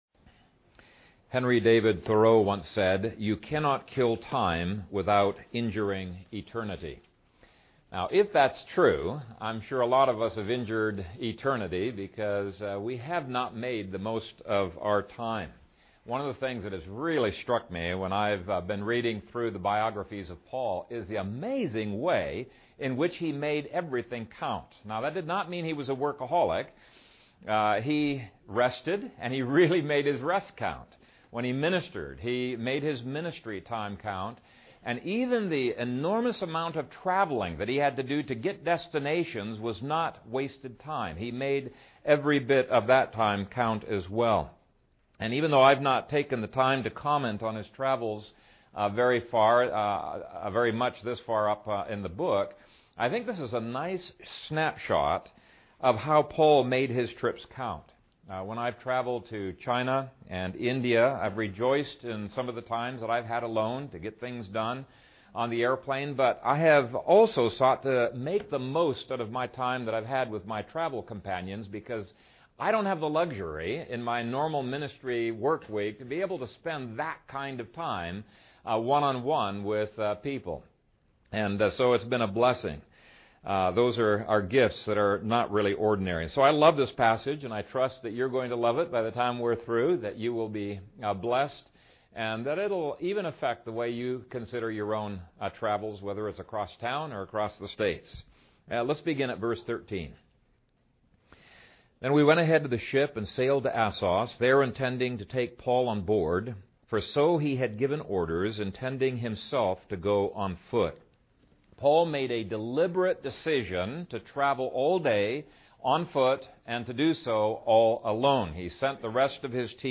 Making the Best of Road Trips | SermonAudio Broadcaster is Live View the Live Stream Share this sermon Disabled by adblocker Copy URL Copied!